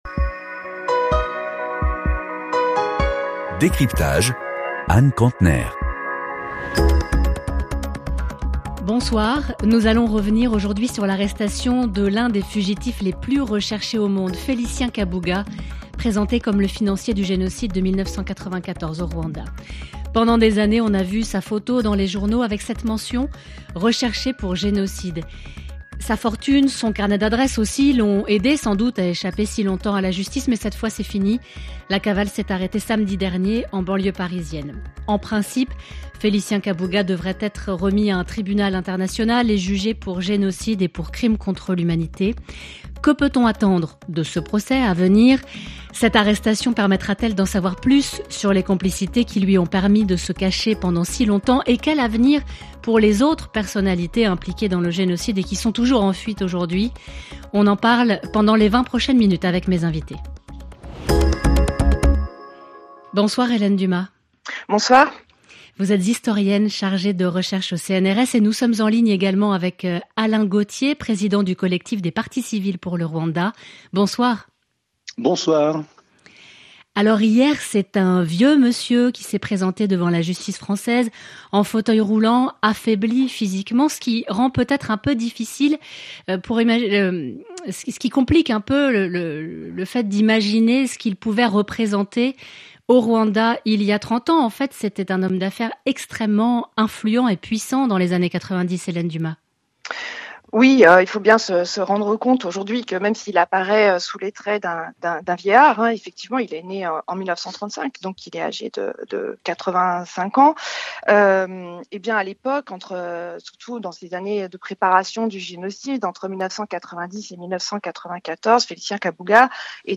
RFI – 21 mai : DÉCRYPTAGE – Que peut-on attendre de l’arrestation de Félicien Kabuga, financier présumé du génocide au Rwanda? Entretien